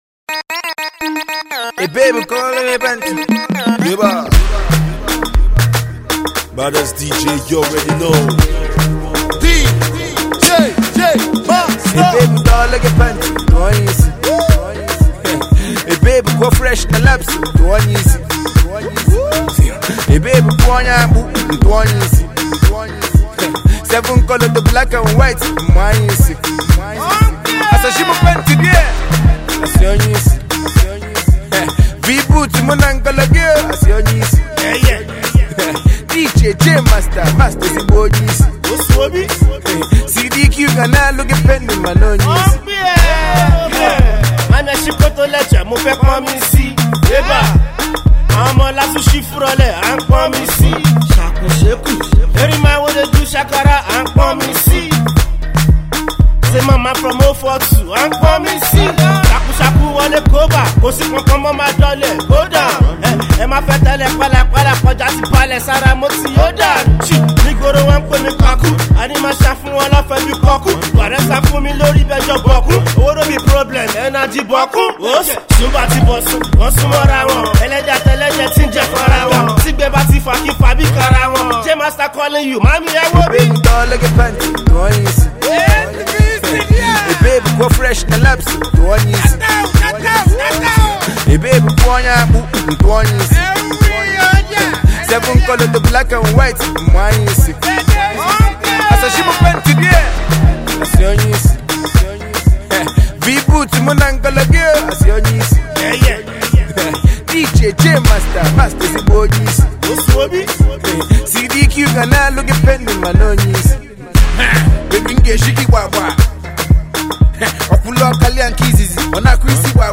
street banger